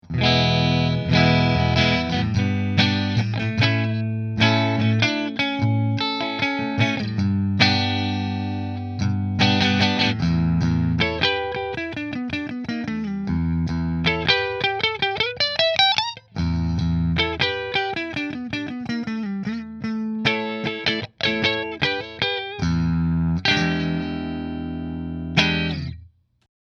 • 2 Custom Wound Humbuckers
New Orleans Guitars Voodoo Custom Redwood Middle Split Through Fender